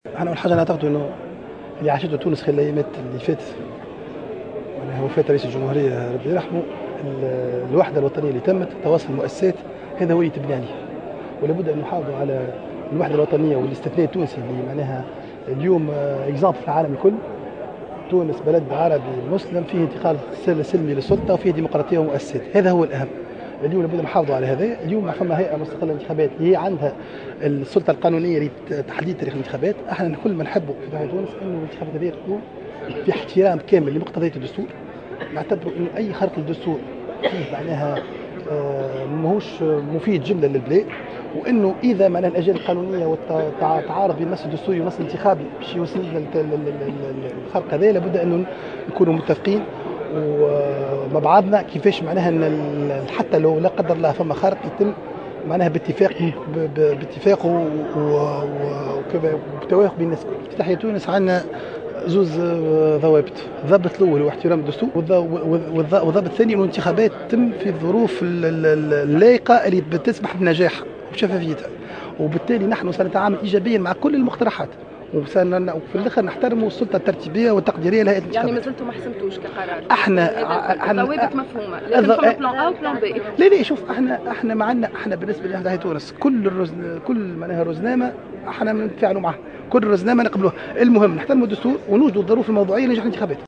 شدد الوزير الأسبق والقيادي في حركة "تحيا تونس"، مهدي بن غربية، في تصريح للجوهرة "أف أم" على ضرورة المحافظة على الوحدة الوطنية وتواصل عمل مؤسسات الدولة وإنجاح النموذج الديمقراطي التونسي، مشيدا بما تجسد من هذه القيم في المرحلة الأخيرة التي مرت بها البلاد إثر وفاة الرئيس الباجي قائد السبسي.